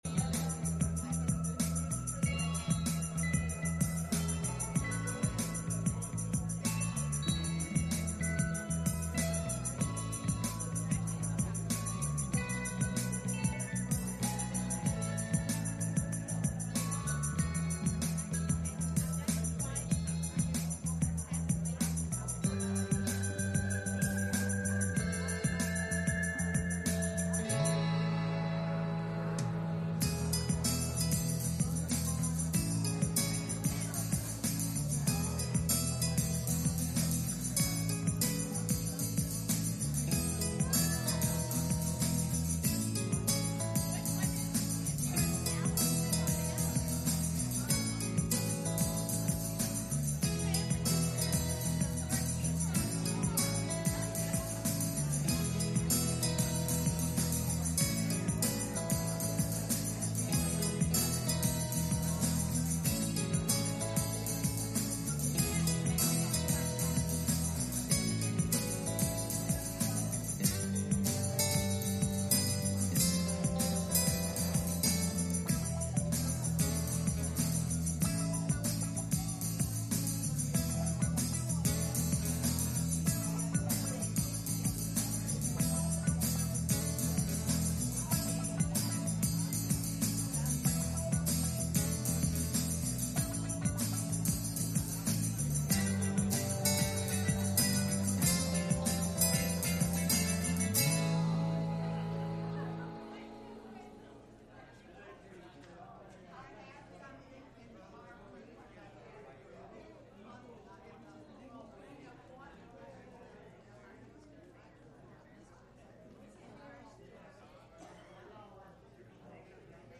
Message Service Type: Sunday Morning « A Look At Demas